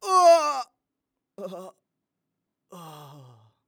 xys死亡1.wav 0:00.00 0:03.68 xys死亡1.wav WAV · 317 KB · 單聲道 (1ch) 下载文件 本站所有音效均采用 CC0 授权 ，可免费用于商业与个人项目，无需署名。
人声采集素材